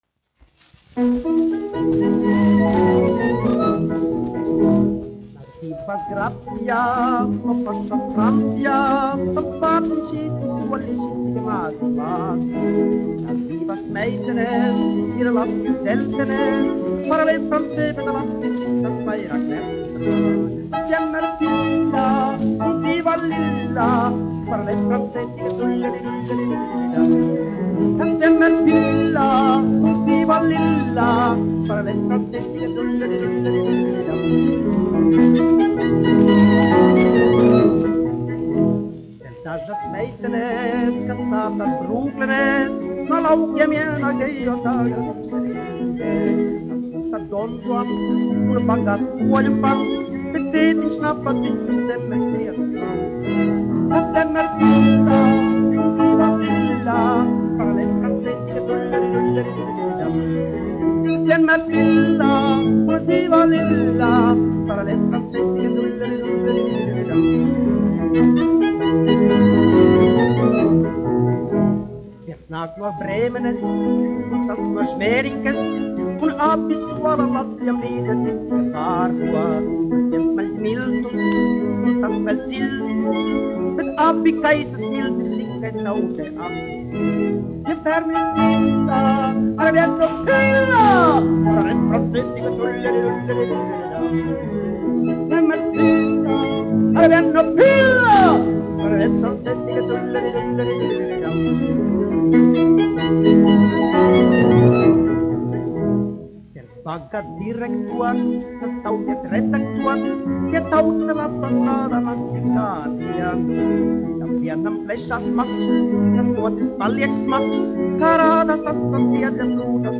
1 skpl. : analogs, 78 apgr/min, mono ; 25 cm
Humoristiskās dziesmas
Latvijas vēsturiskie šellaka skaņuplašu ieraksti (Kolekcija)